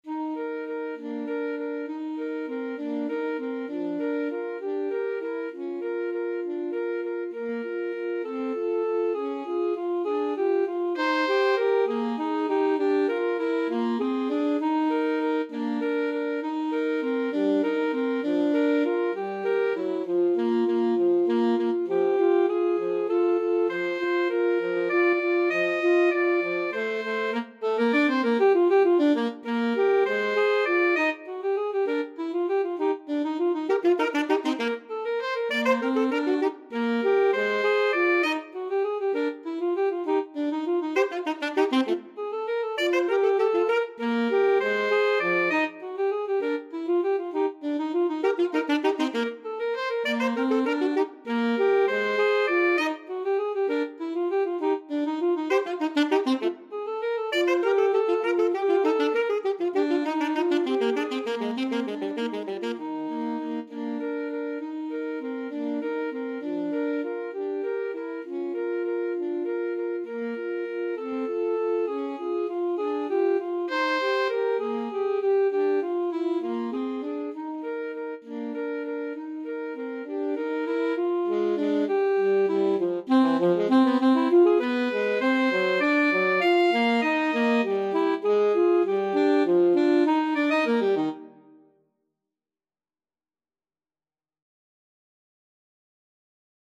Free Sheet music for Alto Saxophone Duet
Eb major (Sounding Pitch) Bb major (French Horn in F) (View more Eb major Music for Alto Saxophone Duet )
One in a bar c. .=c.66
3/4 (View more 3/4 Music)
Classical (View more Classical Alto Saxophone Duet Music)